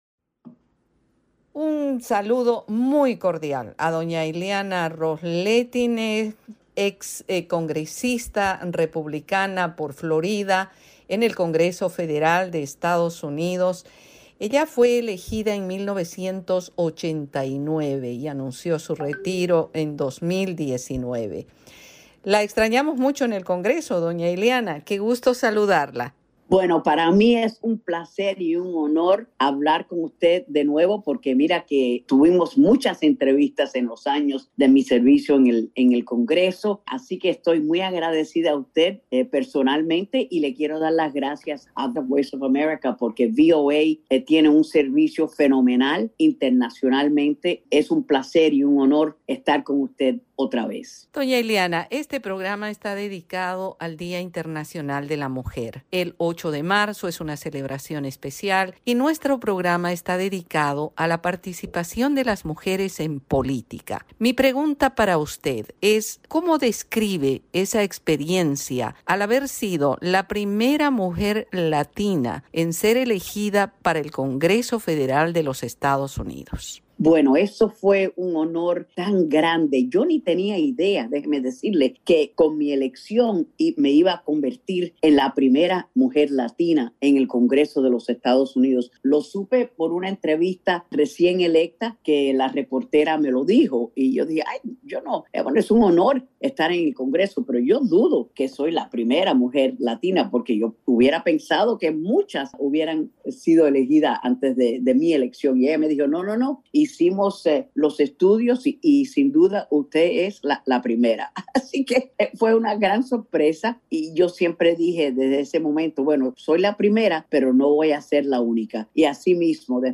Especial Día Internaciona de la Mujer: Entrevista completa Ileana Ros-Lehtinen